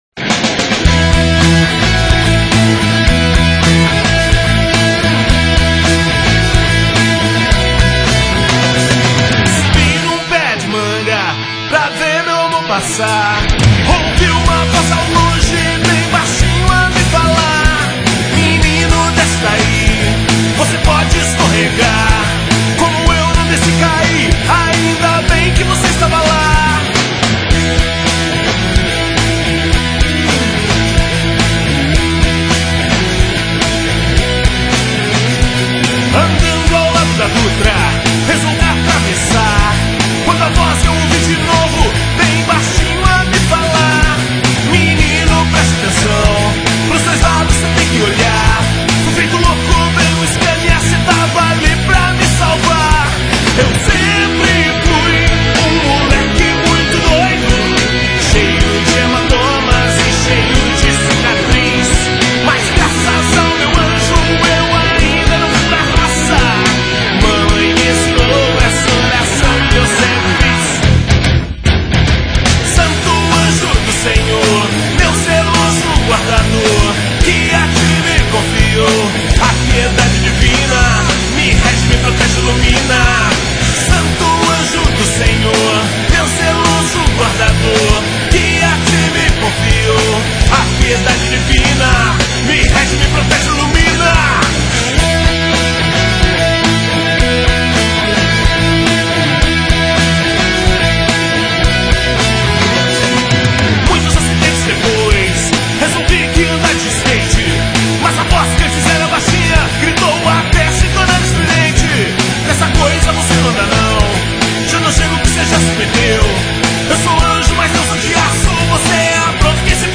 Es gibt sie also doch: eine katholische Punkband!